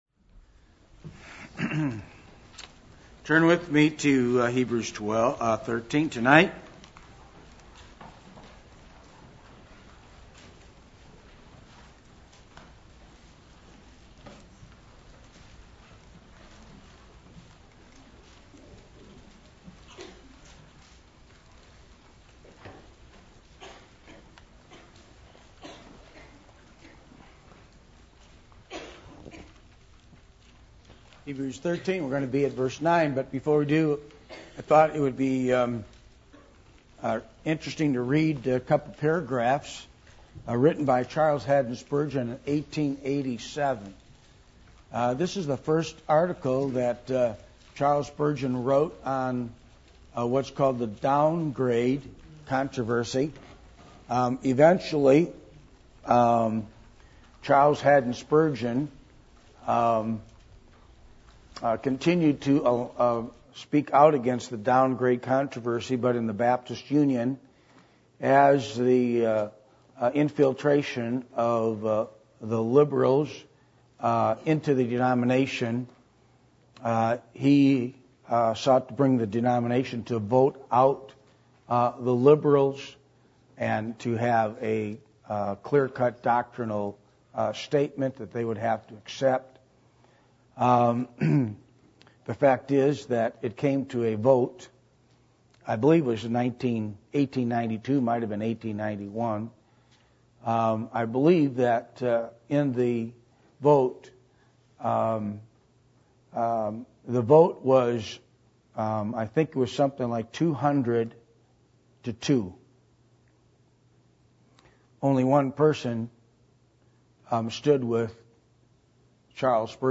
Hebrews 13:8-13 Service Type: Midweek Meeting %todo_render% « The Road To Repentance The Attributes Of God